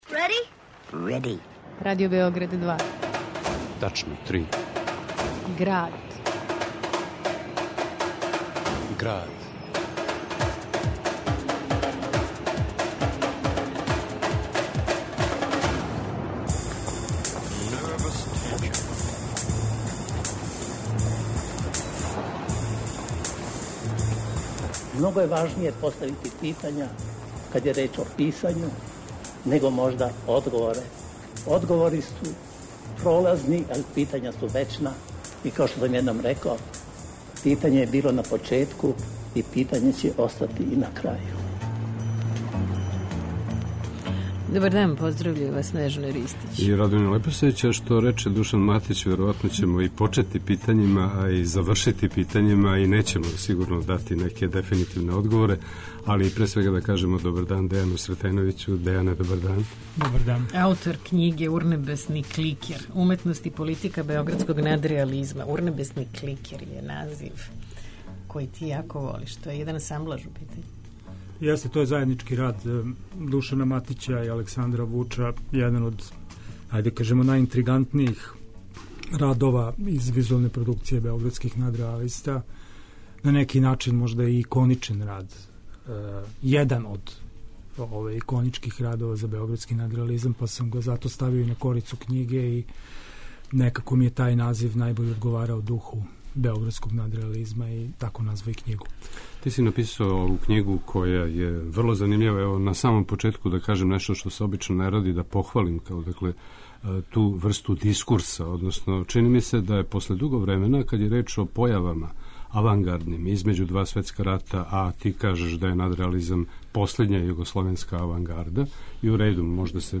У Граду, уз архивске снимке Александра Вуча, Оскара Давича, Марка Ристића и Душана Матића